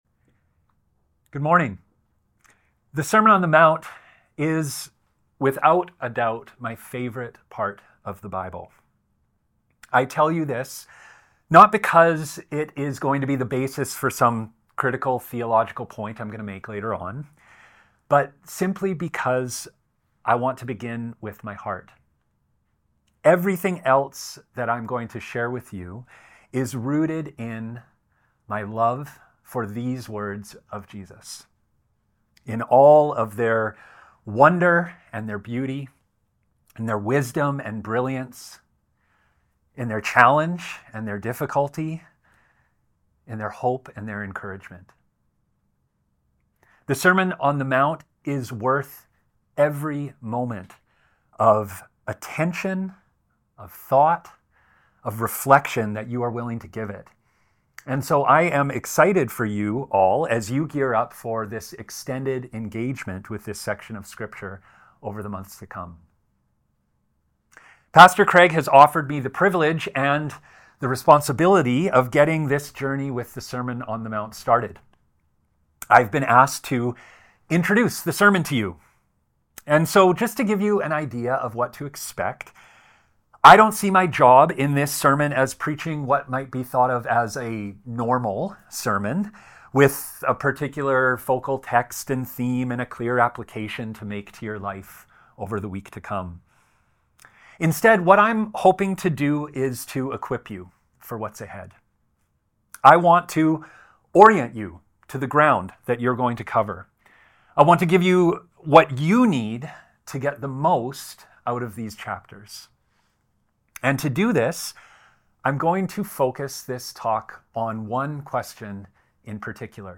Weekly sermons from Ross Road Community Church